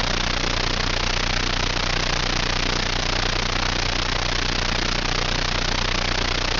RFI caused by Invisible Fence:
AM mode, 3 KHz bandwidth.
invisible-fence.wav